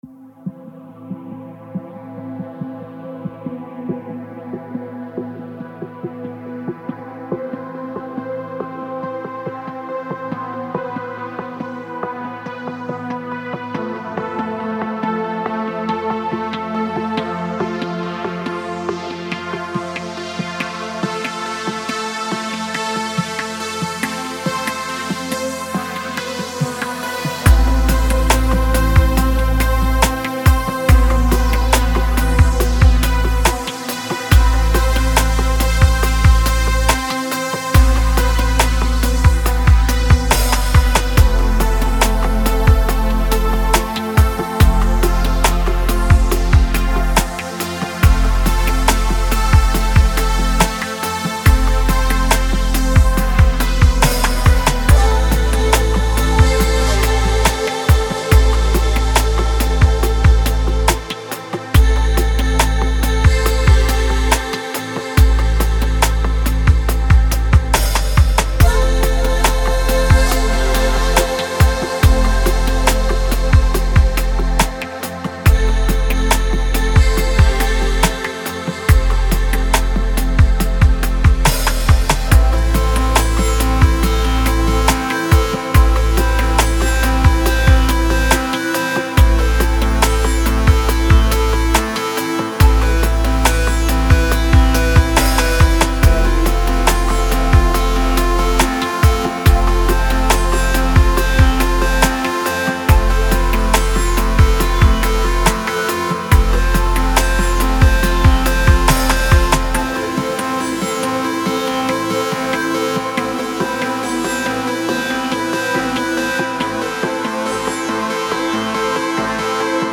音乐风格：Trance / Progressive / Downtempo / Ambient DJ＆producer。